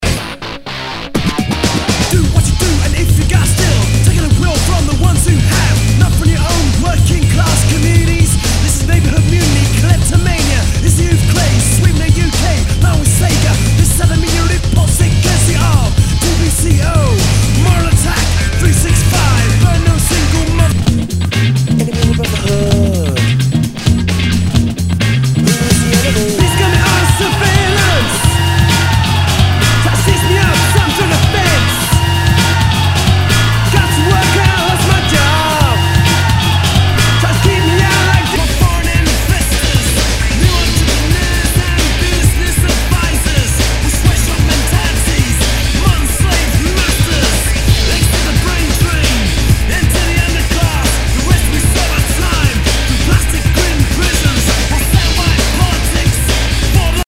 Nu- Jazz/BREAK BEATS
ナイス！インダストリアル/ ロッキン・ブレイクビーツ！